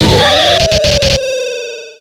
Cri d'Élekable dans Pokémon X et Y.